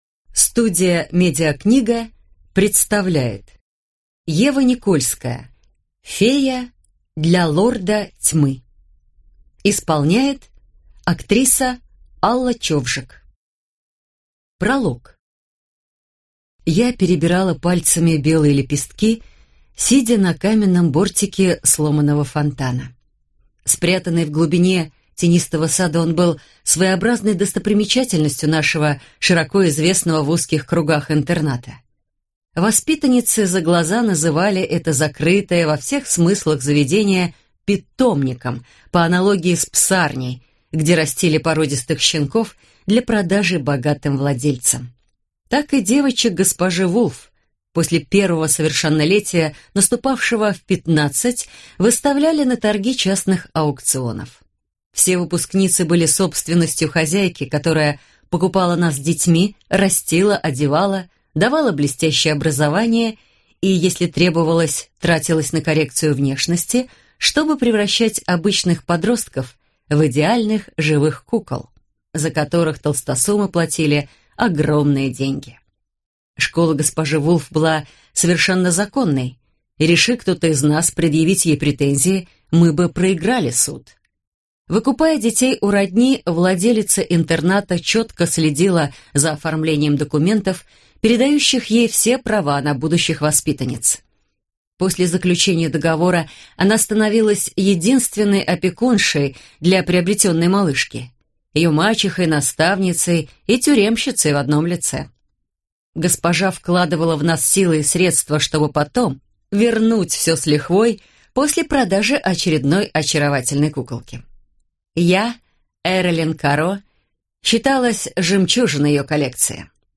Аудиокнига Фея для лорда тьмы | Библиотека аудиокниг